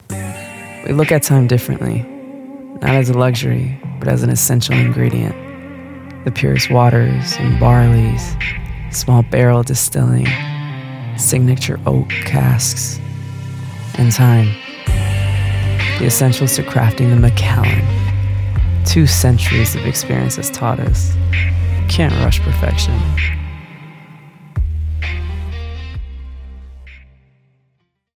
20s-30s. Female. American. Studio.